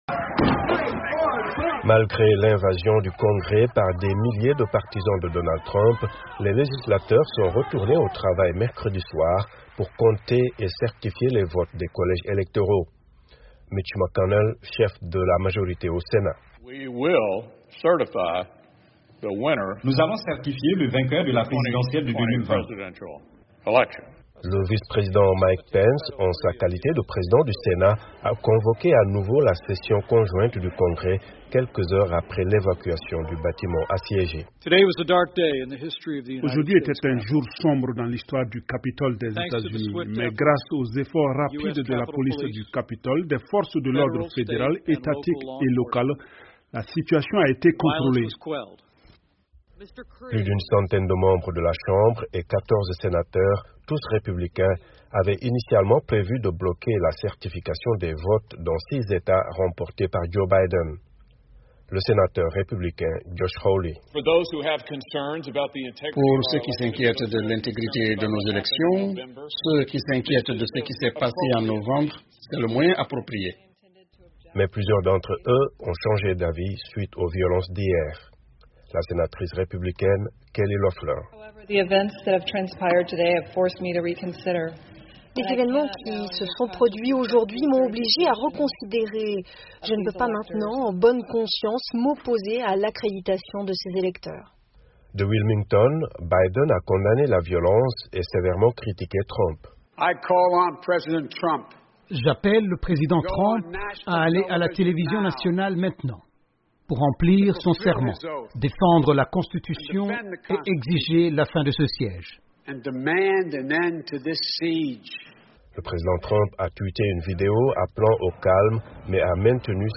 Les membres du Congrès américains ont finalement confirmé la victoire de Joe Biden a la présidentielle du 3 novembre tôt ce jeudi matin. Ils ont repris tard dans la soirée de mercredi le dépouillement des votes du collège électoral, Les législateurs démocrates et républicains se sont montrés déterminés, quelques heures après que des milliers de partisans du président Donald Trump ont pris d'assaut le Capitole. Un reportage